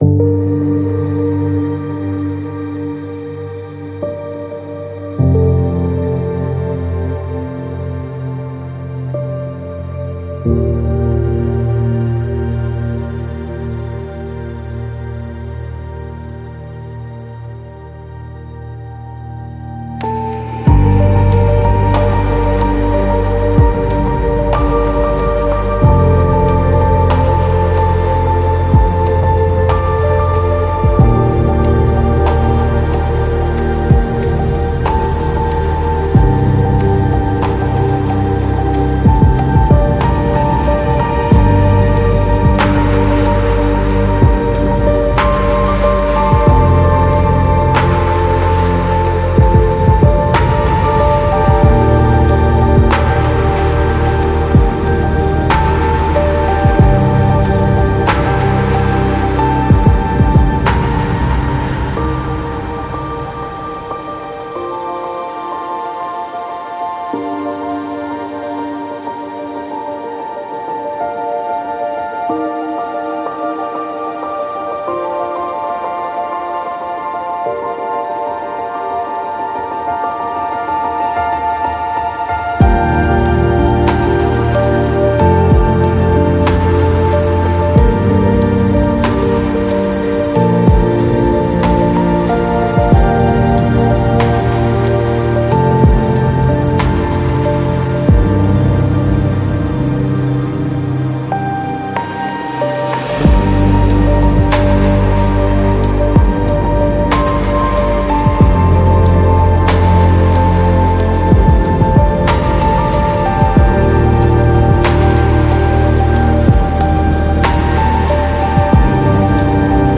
Acoustic
ambient piano